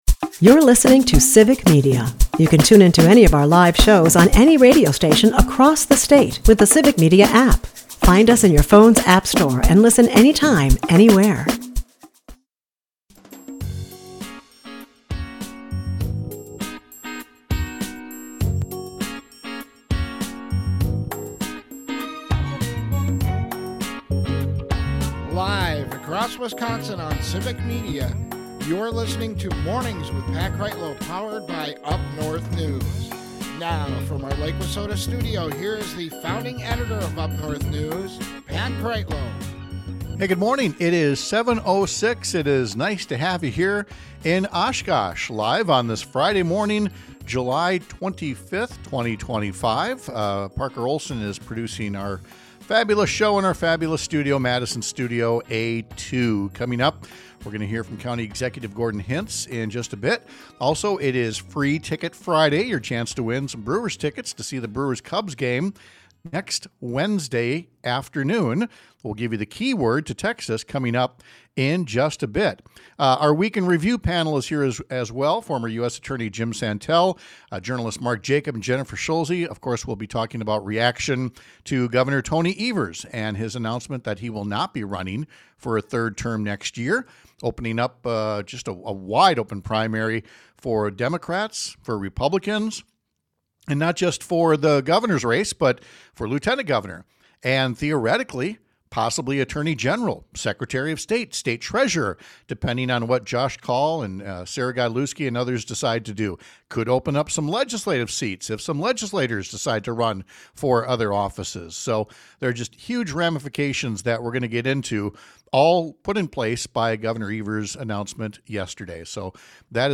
We’ll talk to our Week In Review panel about this week’s political and legal headlines.